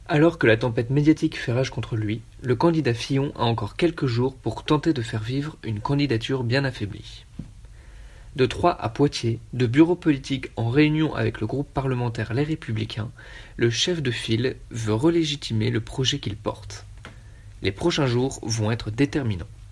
Dessin de presse (c) Christian Larivière Fillon février 2017.mp3 (154.5 Ko) L’ambiance, extrêmement tendue, décrit bien l’importance du moment pour l’avenir du candidat Fillon. Lundi 6 février 2017 dernier, le chef de file de la droite à la présidentielle, empêtré dans un scandale d’emploi fictif, tenait une conférence de presse pour tenter de sortir une bonne fois pour toutes de cette affaire.